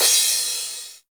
Index of /90_sSampleCDs/Club-50 - Foundations Roland/KIT_xTR909 Kits/KIT_xTR909 3
CYM XC.CRS0A.wav